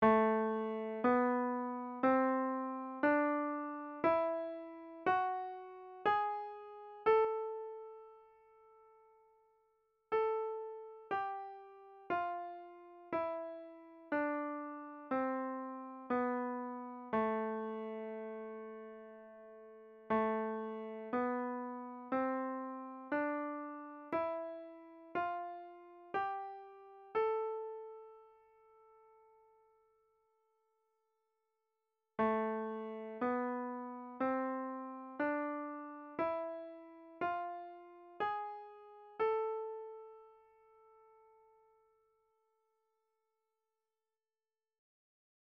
Poslechněte tato dvě ukázky poslechnou a zjistit, v jakém pořadí jsou v nich zaznívají mollové stupnice
mollové stupnice a moll.mp3